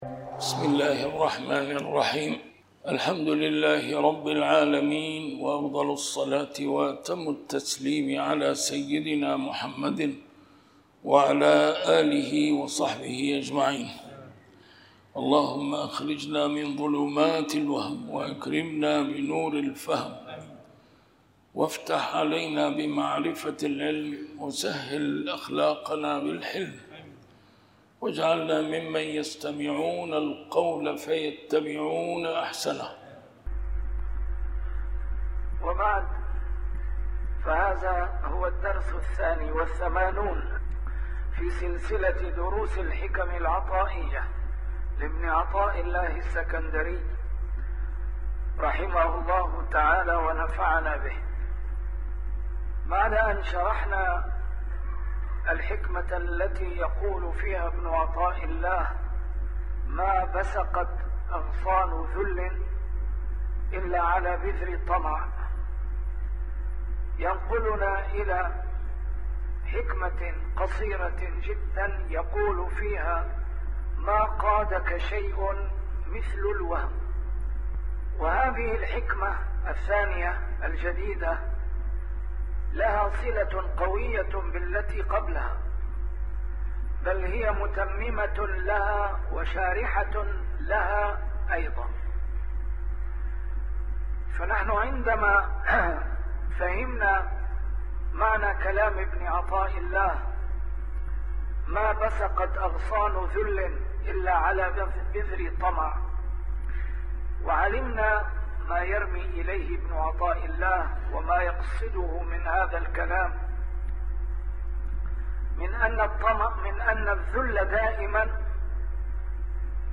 A MARTYR SCHOLAR: IMAM MUHAMMAD SAEED RAMADAN AL-BOUTI - الدروس العلمية - شرح الحكم العطائية - الدرس رقم 82 شرح الحكمة 61